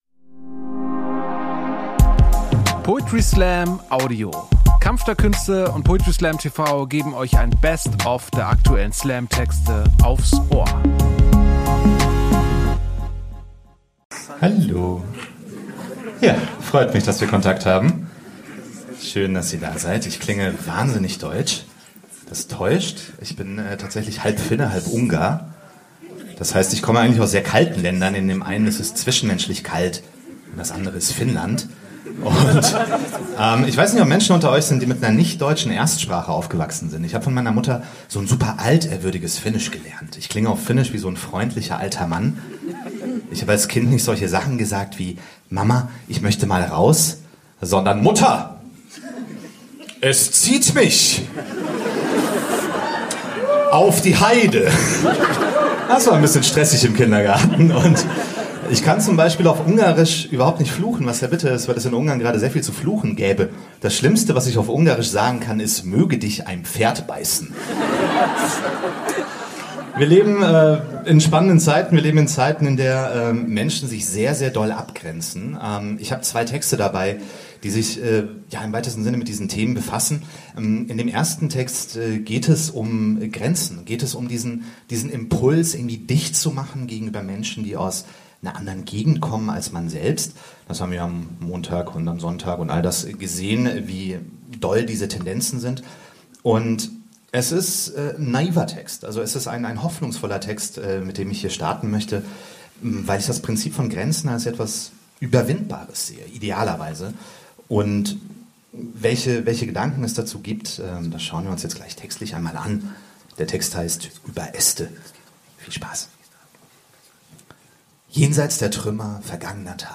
Ernst Deutsch Theater, Hamburg Poetry Slam TV Website: